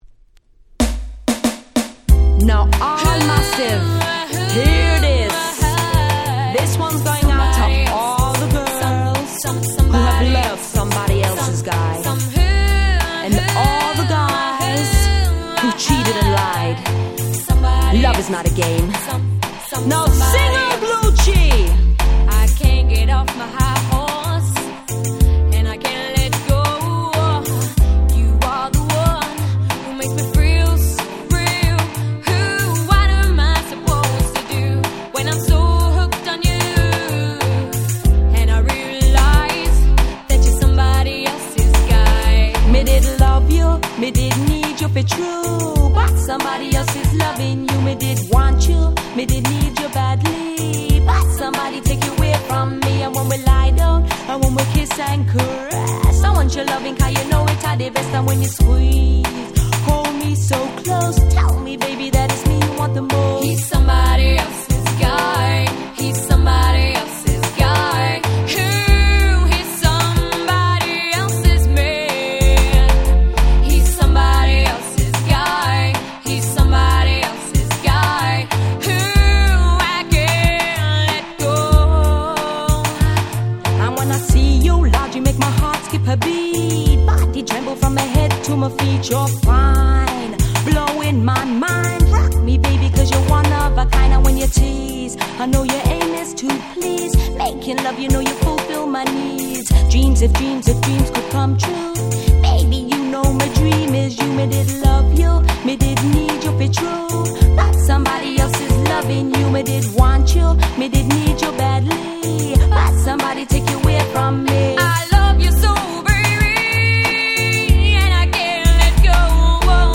93' Very Nice Cover Lovers Reggae !!